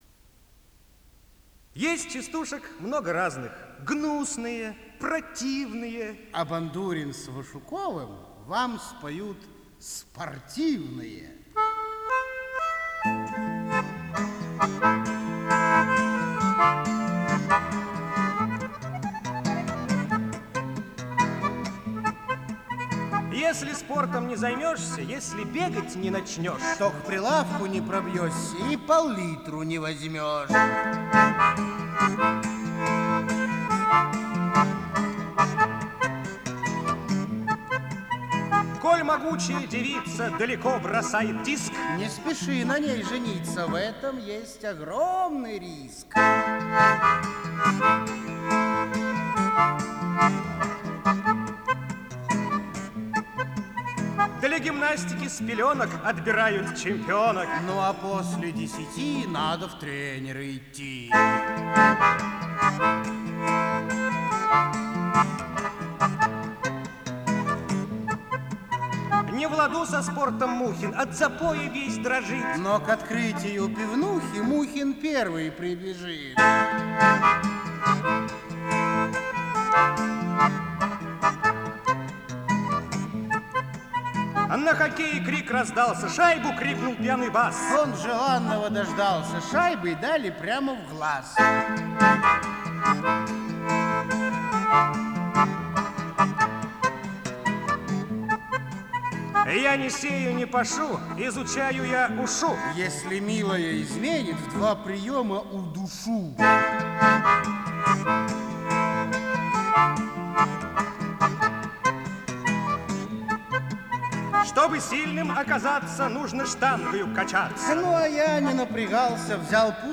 Жанр: Авторская песня / Куплеты